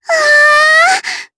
Rodina-Vox_Happy4_jp.wav